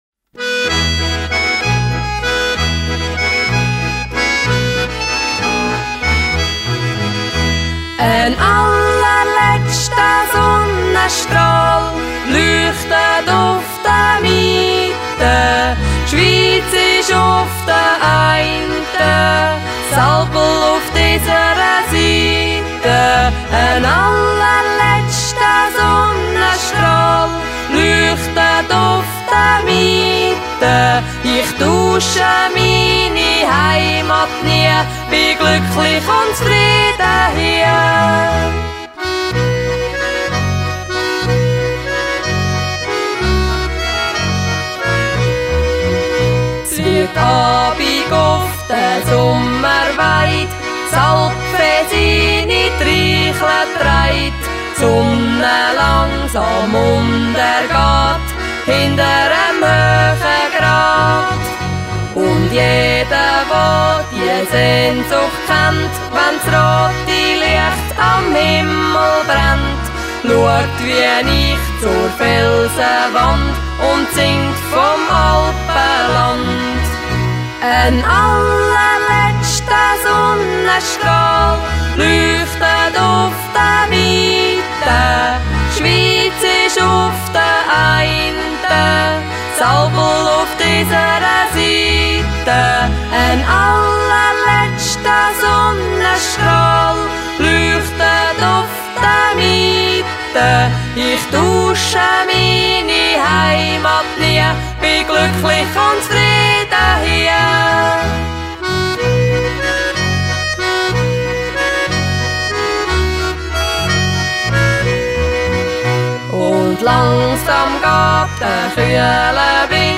Swiss folk songs.